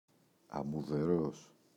αμμουδερός [amuðe’ros]